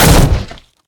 wood_crash_hl2.ogg